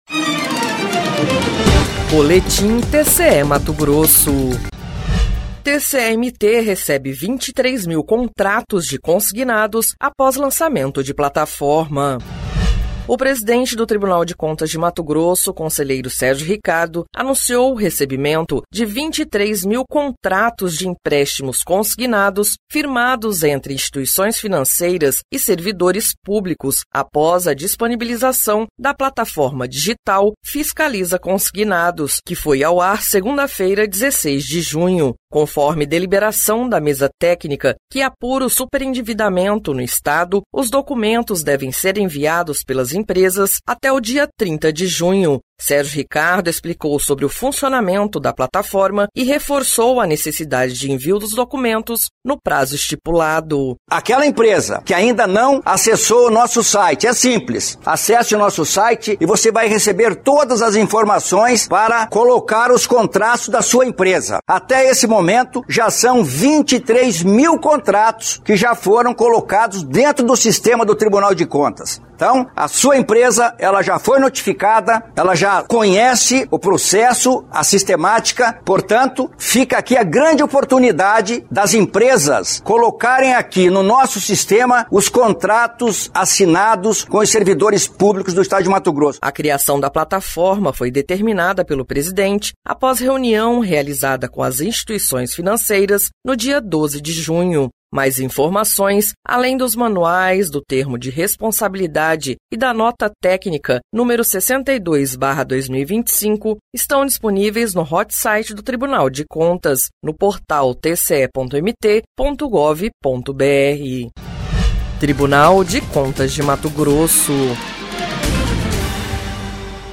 Sonora: Sérgio Ricardo – conselheiro-presidente do TCE-MT